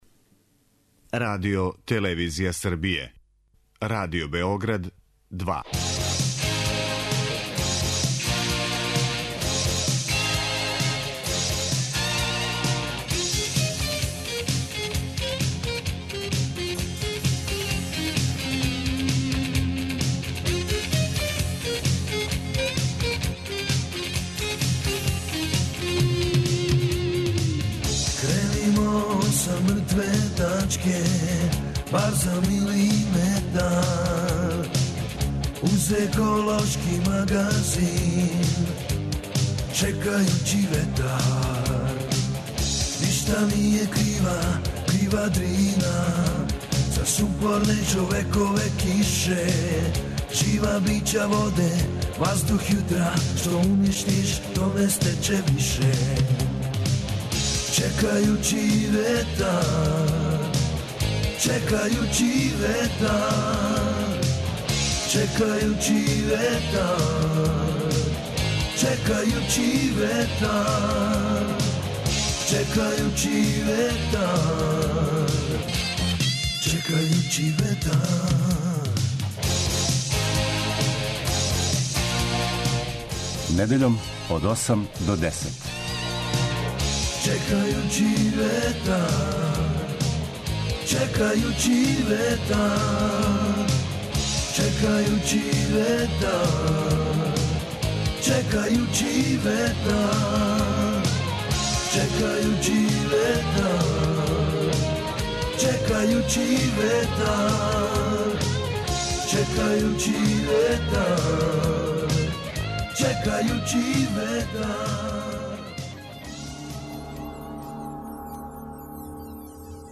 Еколошки магазин који се бави односом човека и животне средине, човека и природе.